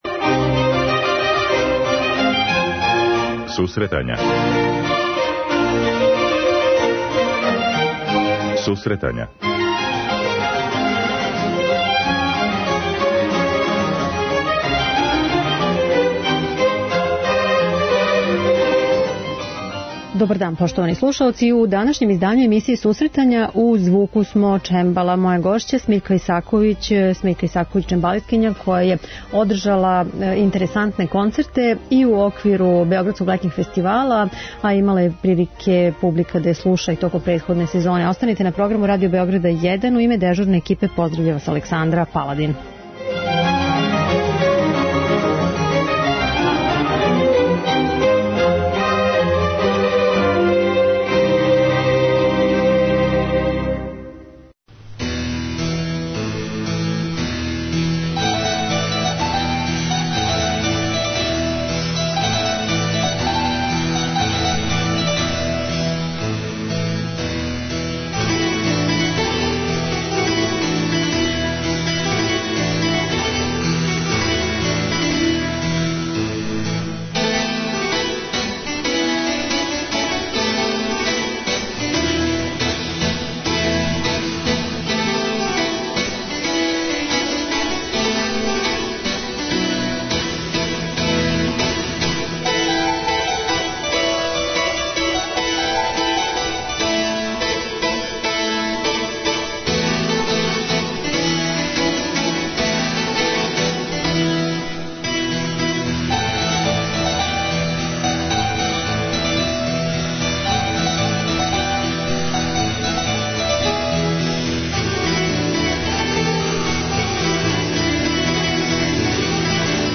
Данас са овом уметницом разговарамо о концерту, положају музичких уметника, менаџменту у култури и актуелним музичким темама.